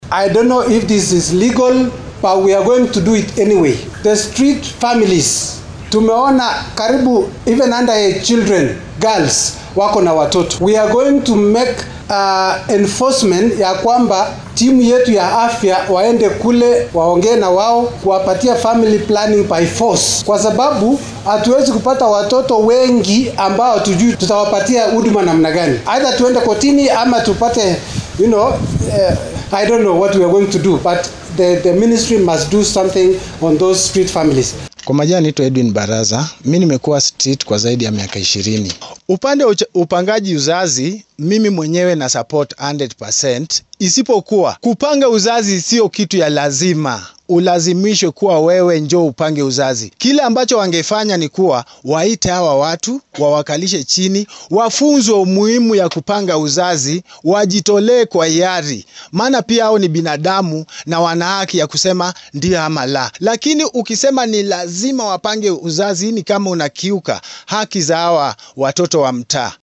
Speaking to the press Uasin Gishu county Assembly leader of majority Julius Sang says the move will reduce the number of street urchins in Eldoret CBD that are currently estimated to be over 3,000.
Hon Julius Sang Leader of majority Uasin Gishu County Assembly
sound-bite-julius-sang-1-1.mp3